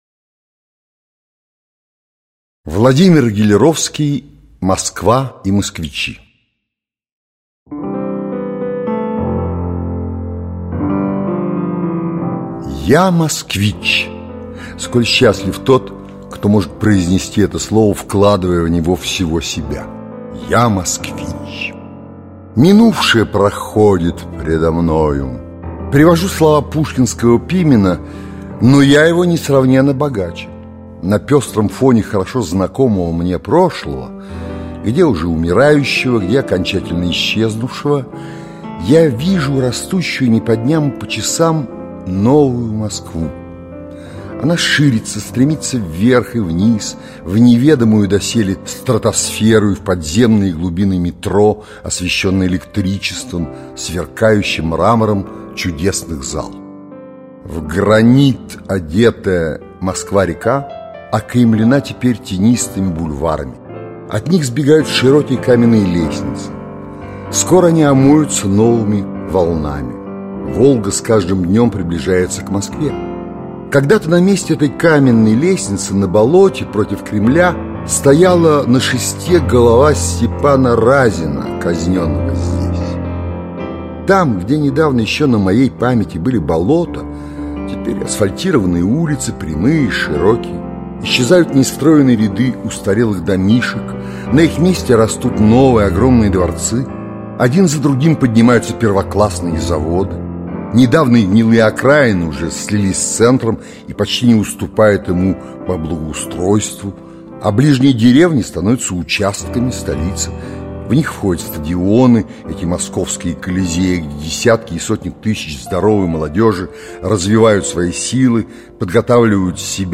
Аудиокнига Москва и москвичи | Библиотека аудиокниг